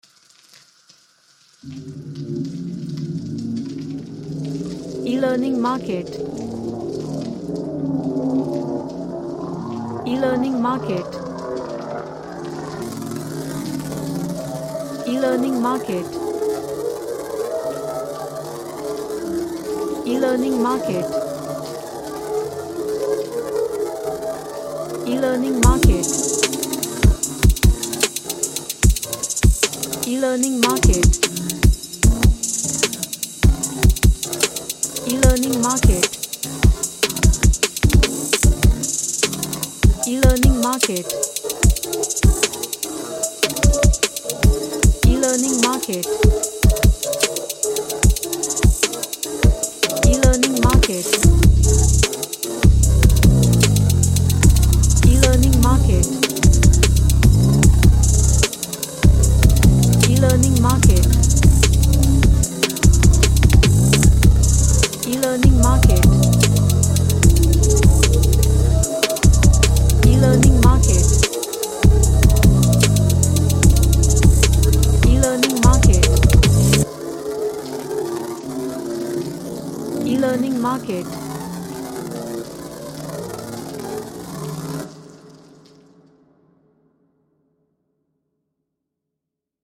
A dark sounding hip hop track
Dark / Somber